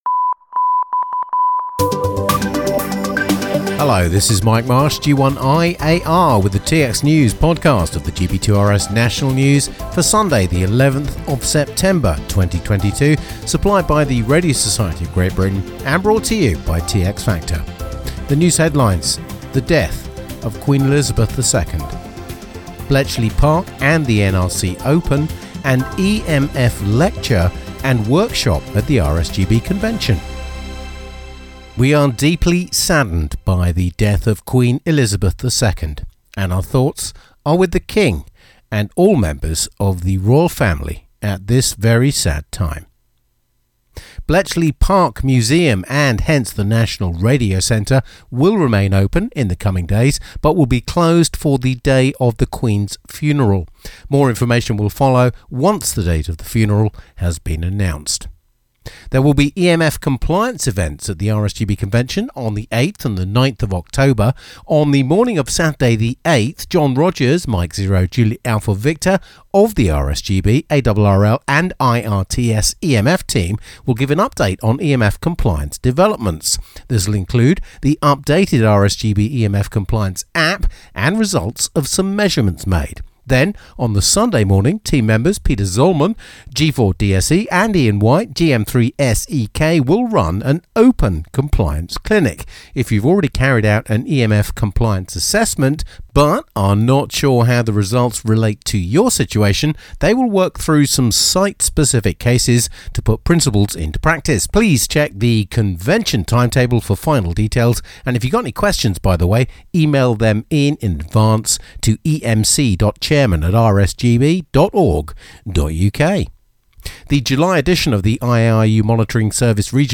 Audio features and interviews dedicated to the world of amateur radio from the folks at TX Factor